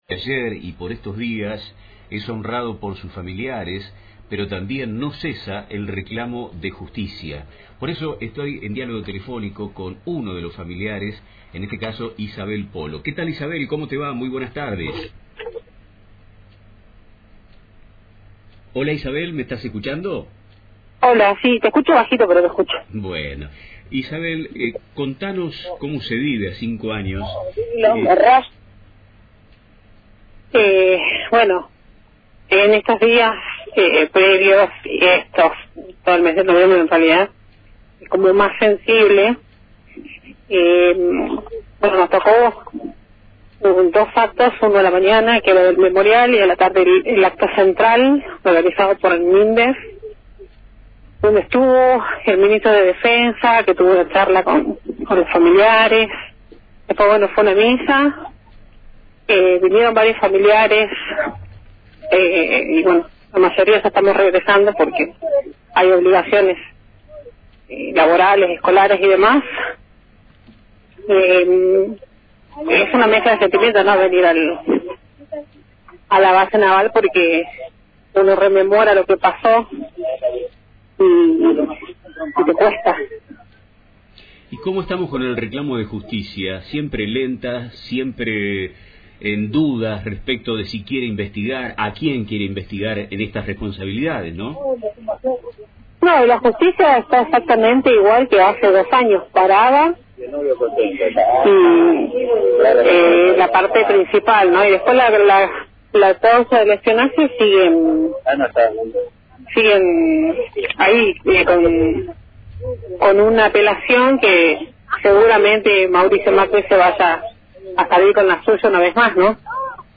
Radio LT35 Pergamino - Entrevista